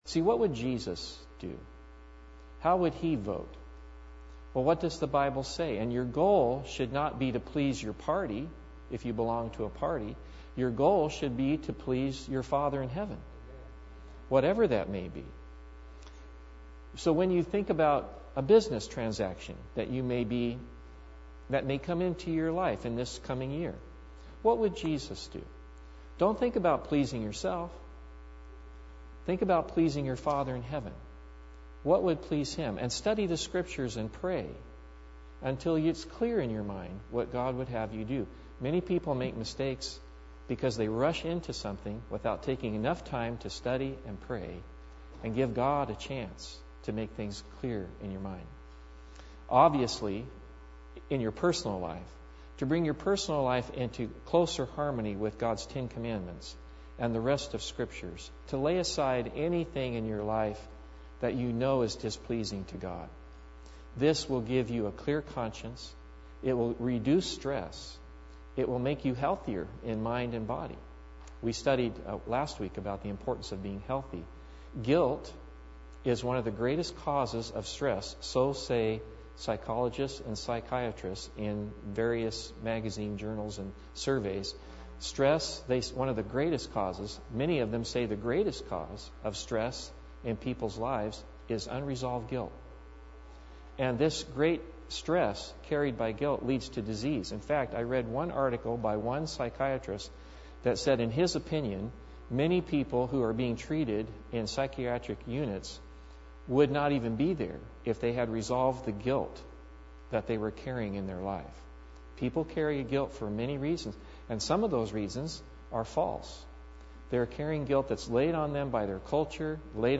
I John 3:2 Service Type: Sabbath Bible Text